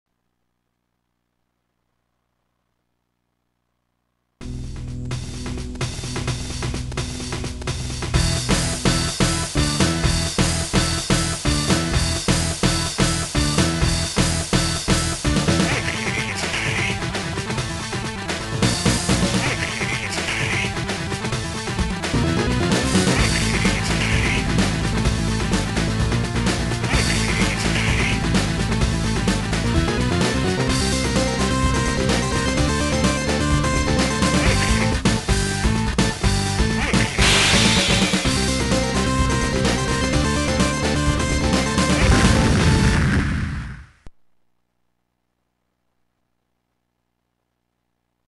intro song